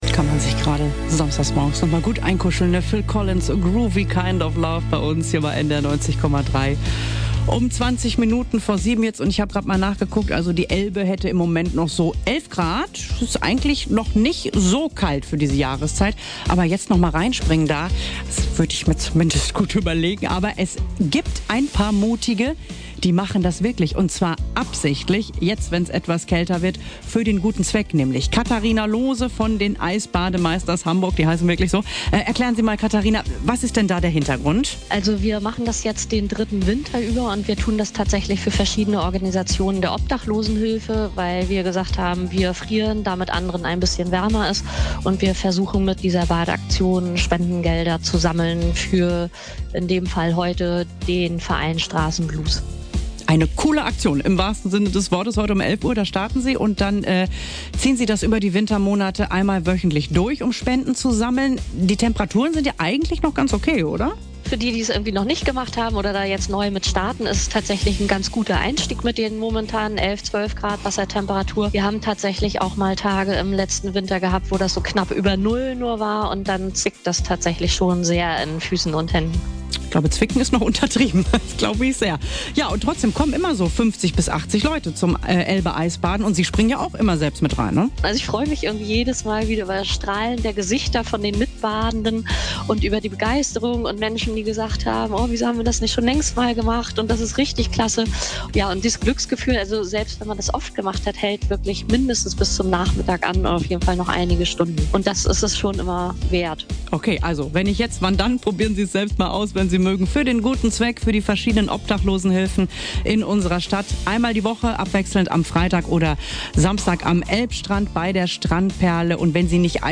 Ein Radiobericht für Frühaufsteher